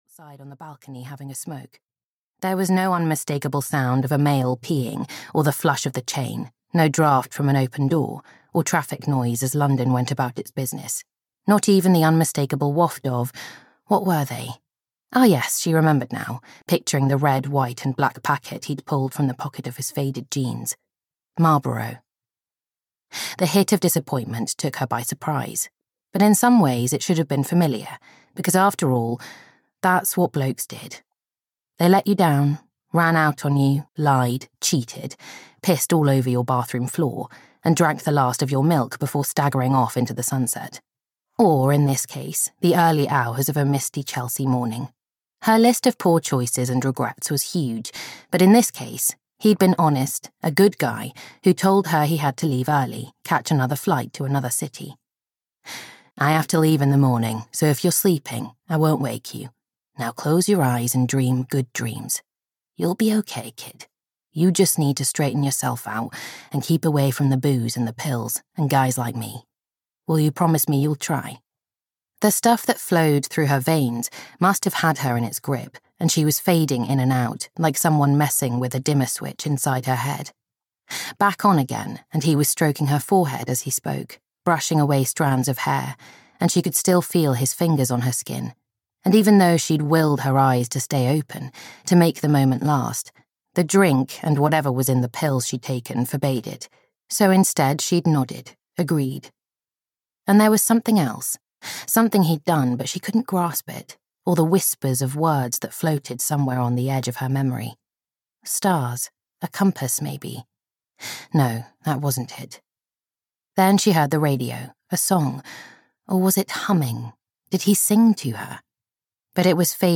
Venus Was Her Name (EN) audiokniha
Ukázka z knihy